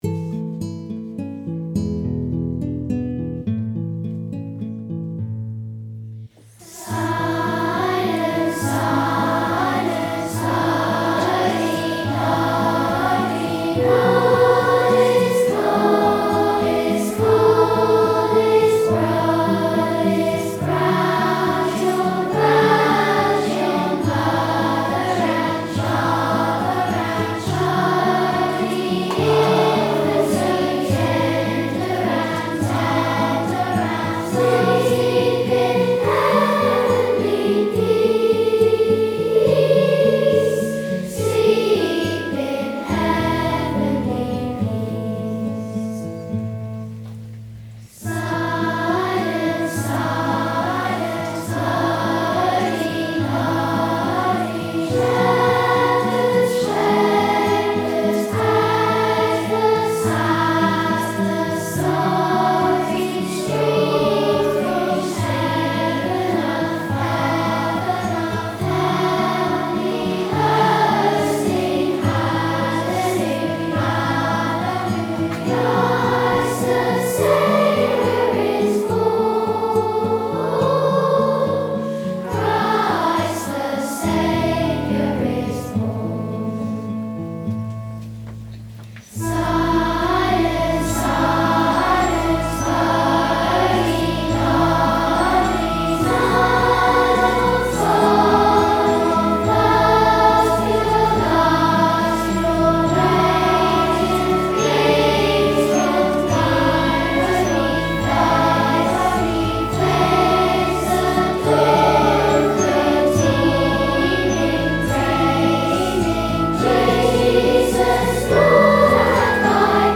Choir at St Pauls
DCAT Christmas Competition Winners